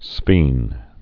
(sfēn)